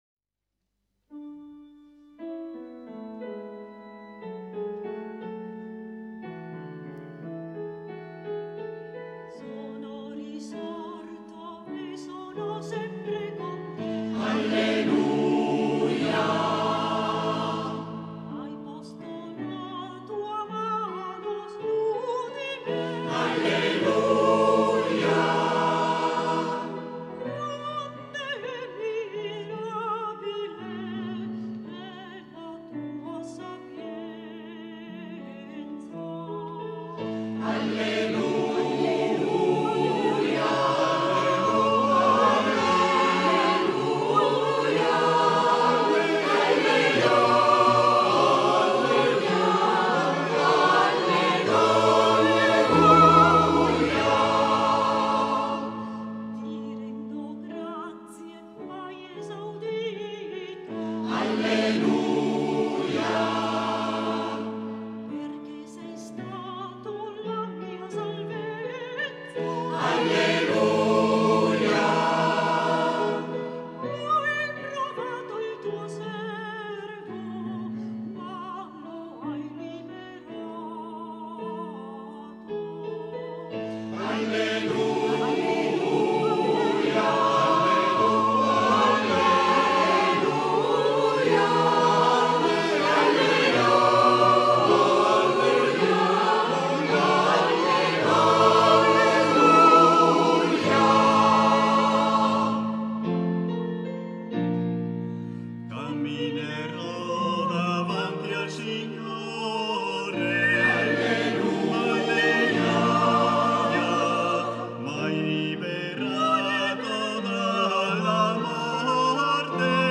Ascoltiamo un canto pasquale del coro,